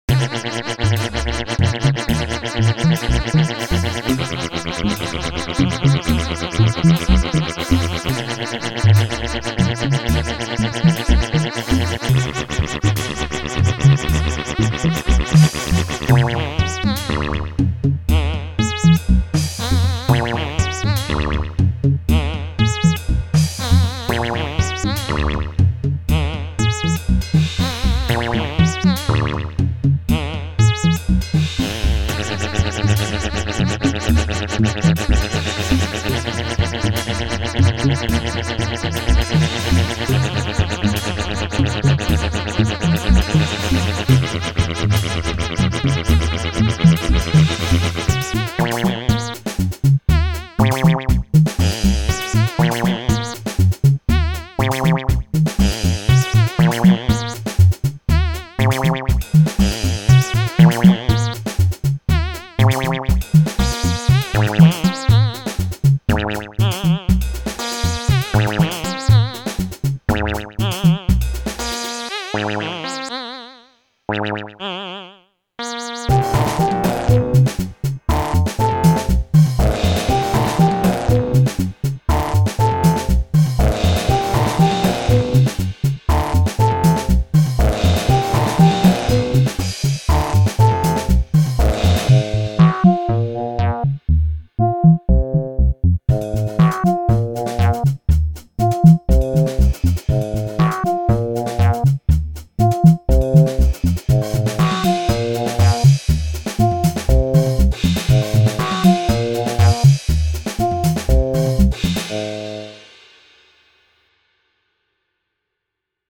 Four synth parts played polyphonically: sustained chords, then some permutational melodies. (Not using the modular, this is a conventional analog I was testing out before taking it to the shop for some knob repair - more info than you needed, sorry.) At the end, FM modulation adds grit, and I really like the tune at 1:15, which sounds sort of Canterburyish (Egg?), not sure why.
Bass lines and conventional jazz drums were added to make the "trio" with the imaginary synth player.